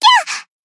BA_V_Mutsuki_Battle_Damage_1.ogg